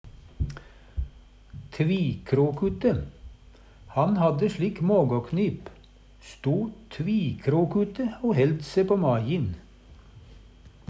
tvikrokute - Numedalsmål (en-US)